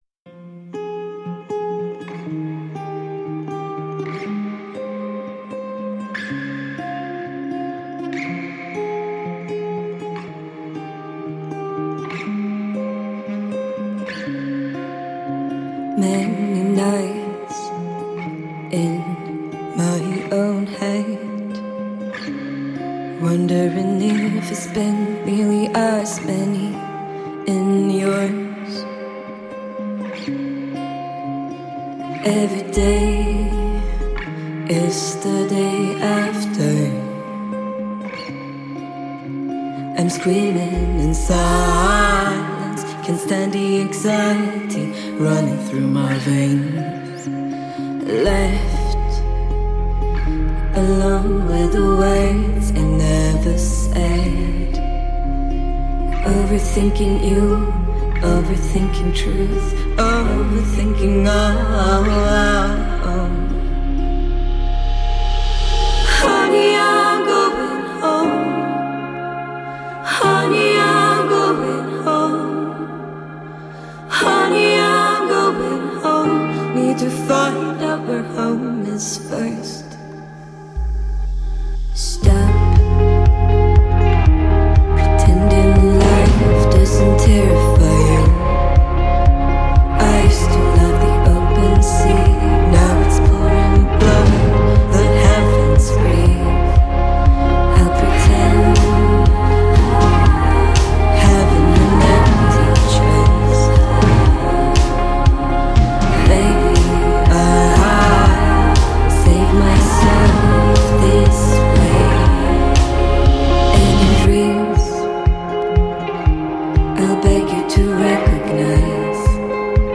melancholisch, düster und ziemlich wundervoll